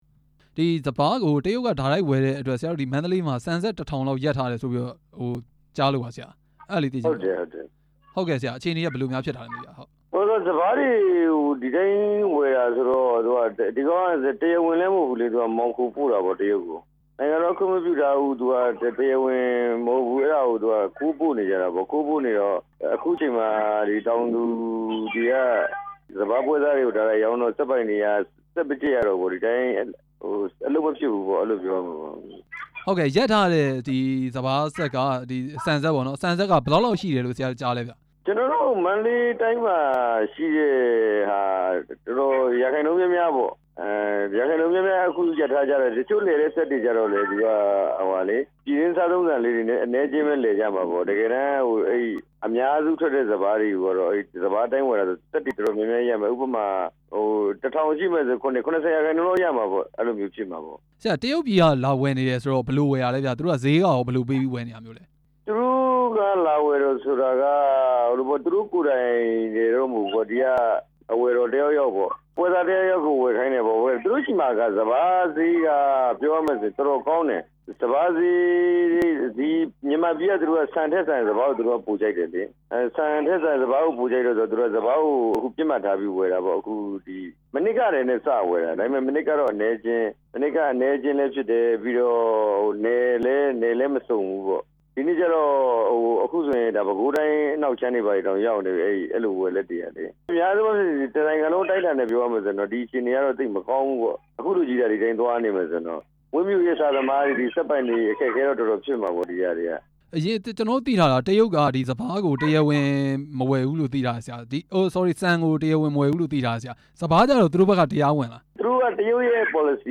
စပါးကို တရားမဝင်ဝယ်ယူနေတဲ့ တရုတ်ကုန်သည်တွေအကြောင်း မေးမြန်းချက်